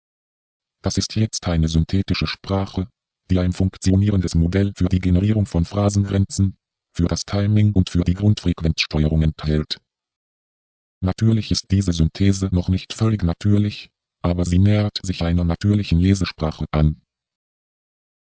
Erst mit einer korrekten Phrasierung wird ein komplexerer Text überhaupt verständlich (